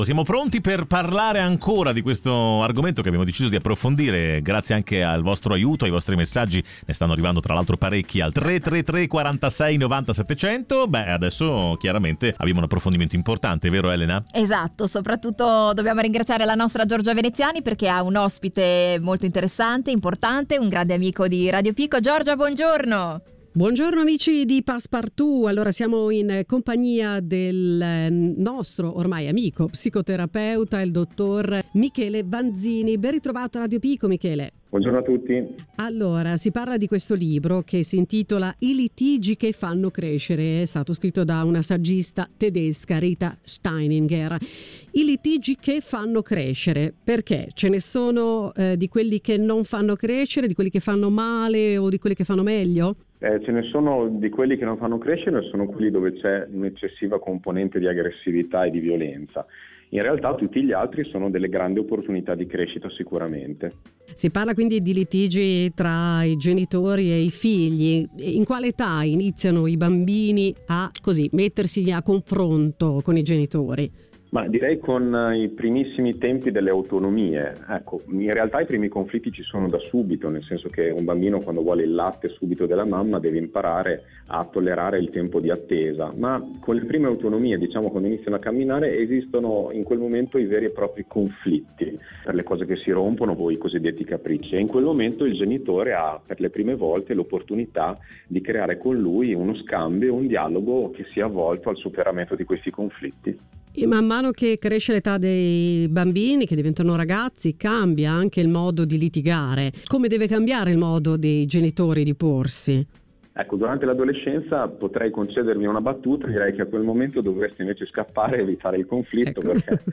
psicologo e psicoterapeuta.